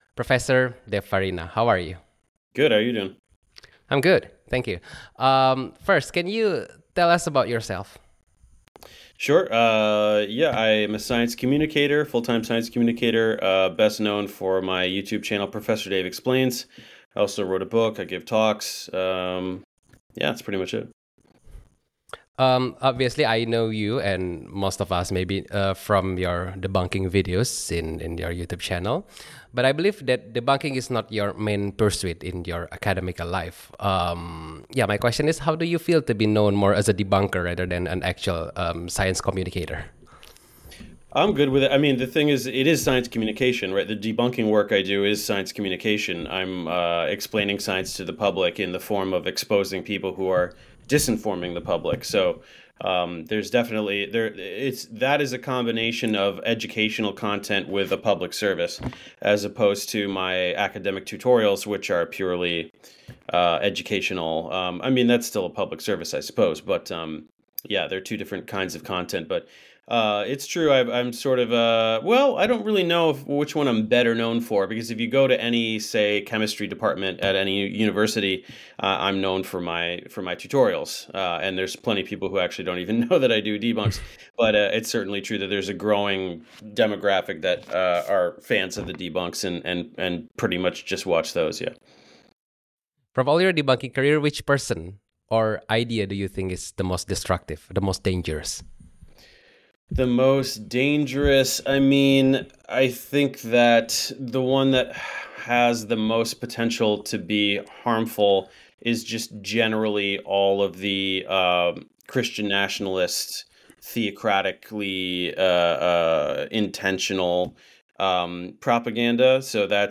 Obrolan saya bersama teman saya